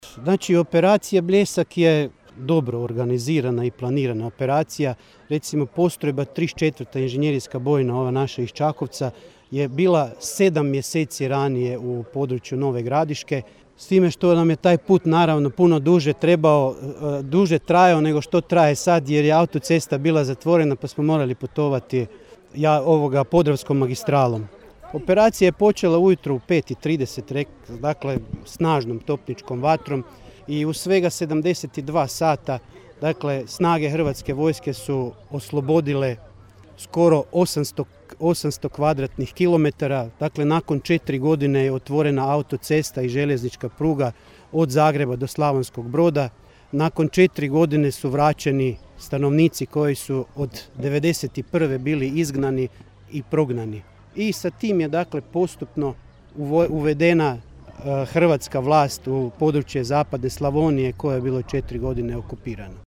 VRO Bljesak, obilježavanje u Čakovcu, 1.5.2022.